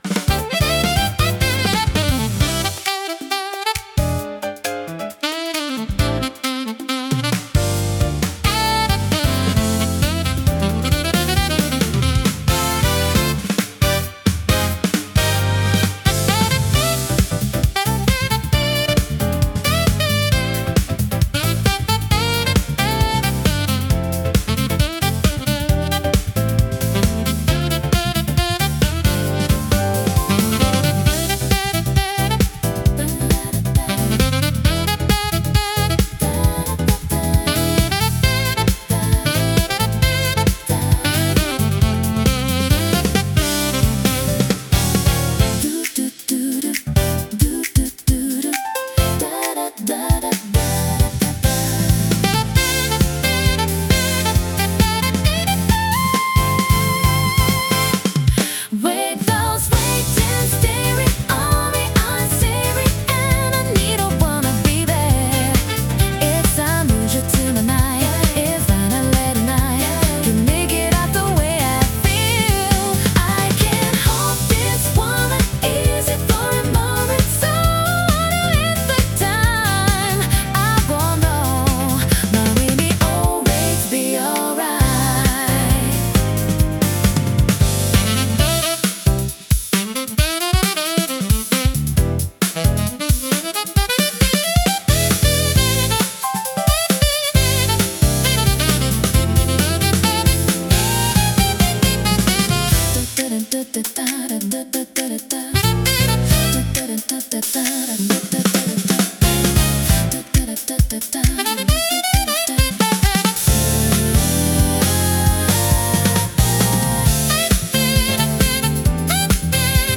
イメージ：ドゥーワップ,ジャズ,ピアノグルーブ,女性ボーカル,アルトサックスリフ,おしゃれ